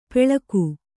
♪ peḷaku